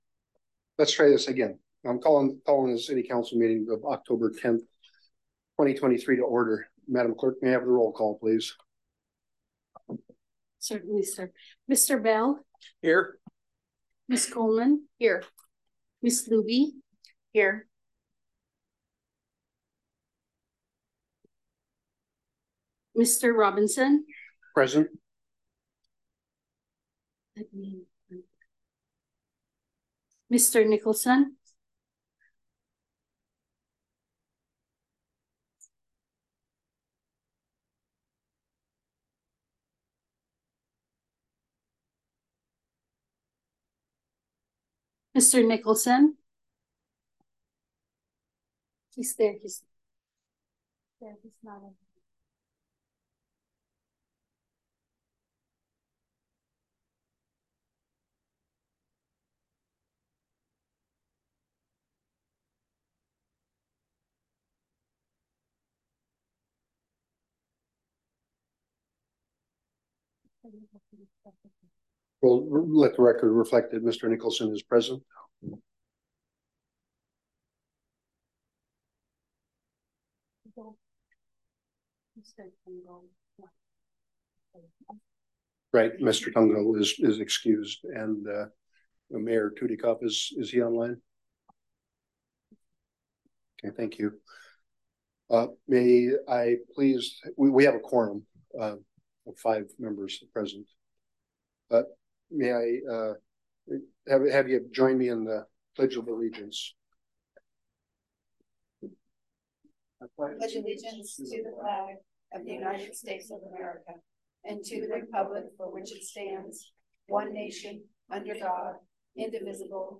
City Council Meeting - October 10, 2023 | City of Unalaska - International Port of Dutch Harbor